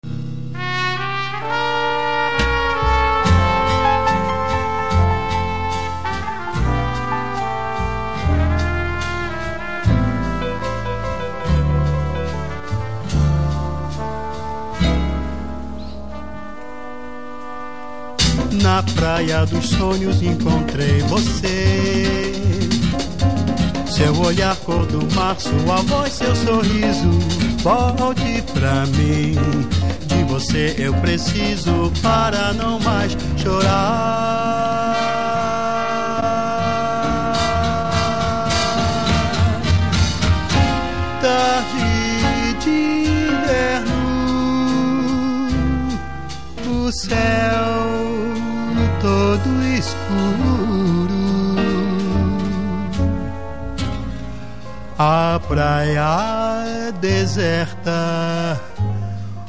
Here the bossa nova pioneer sings beautiful songs
flute
drums